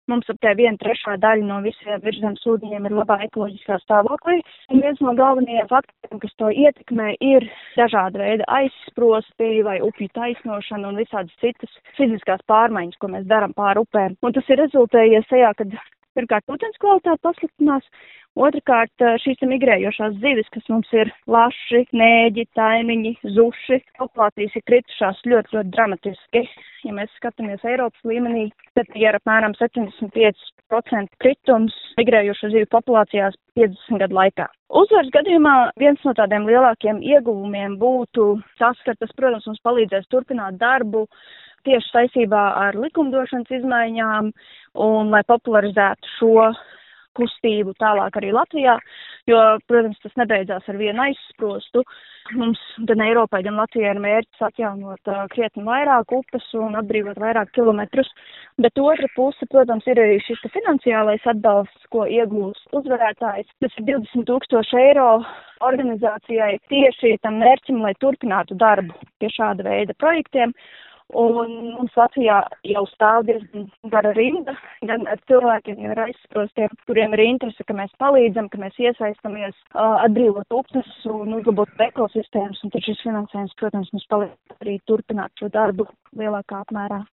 intervijā Skonto mediju grupai